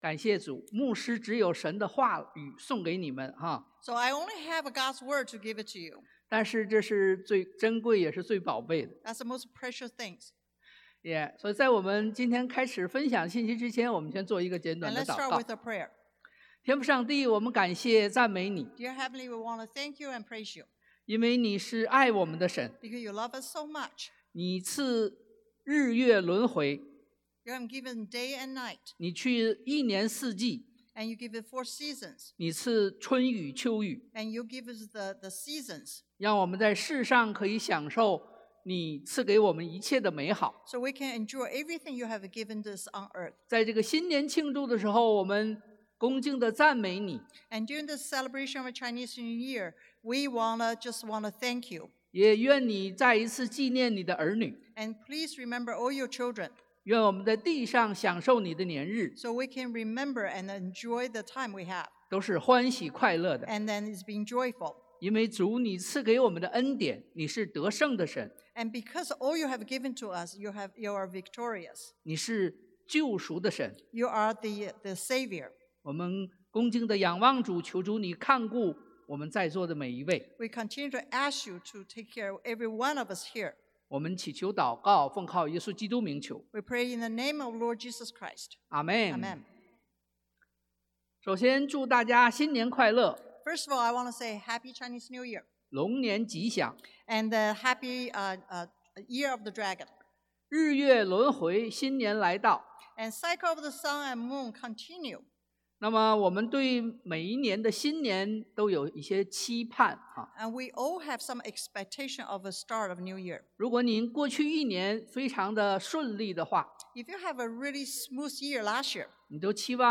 賽 Isa. 61:1-2 Service Type: Sunday AM « 2024-02-04 Take Every Thought Captive 掌握每個念頭 2024-02-18 Why Don’t You Do What I Say?